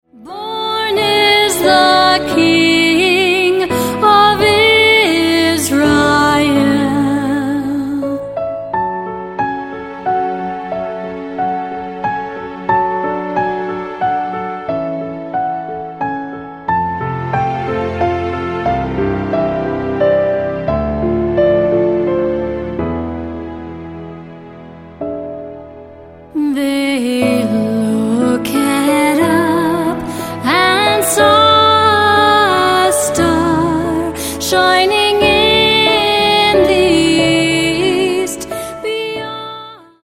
Piano - Strings - Low - Vocal